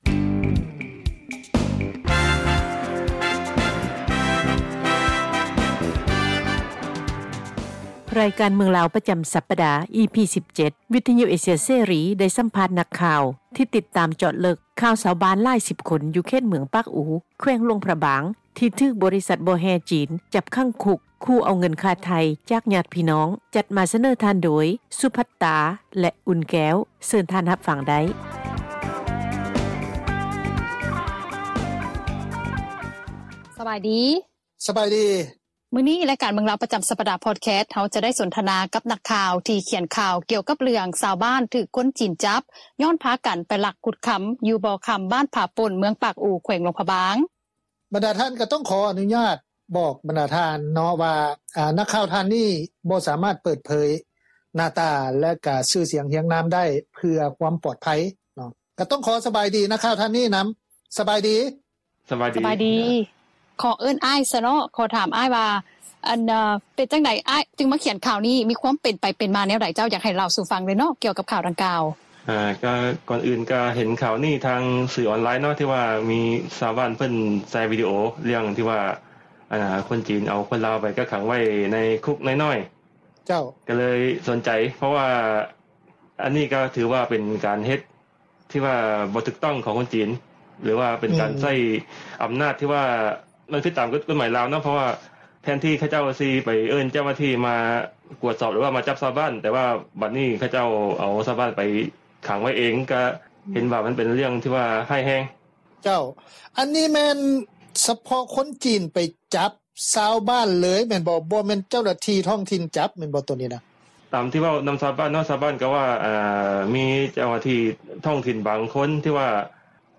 ສໍາພາດ ນັກຂ່າວ ທີ່ເຈາະເລິກ ຂ່າວ ຊາວບ້ານຫຼາຍສິບຄົນ ຖືກບໍລິສັດ ບໍ່ແຮ່ຈີນ ຈັບຂັງຄຸກ-ຂູ່ເອົາເງິນຄ່າໄຖ່